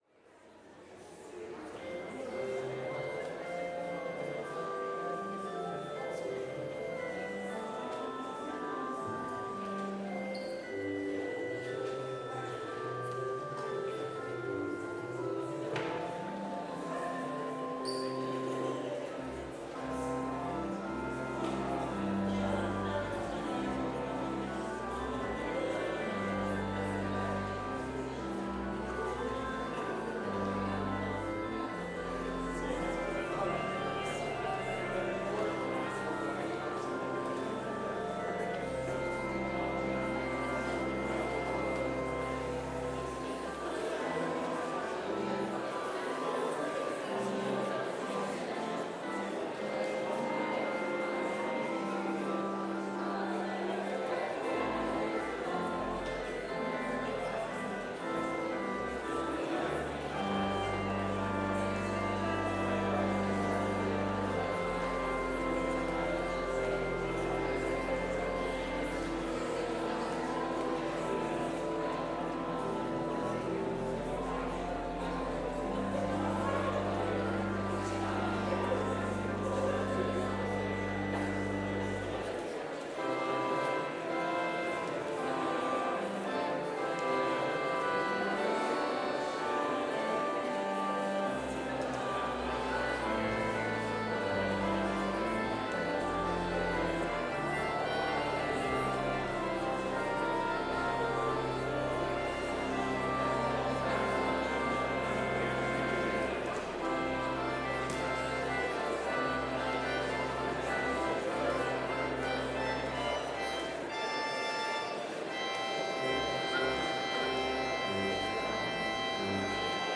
Complete service audio for Chapel - Wednesday, October 30, 2024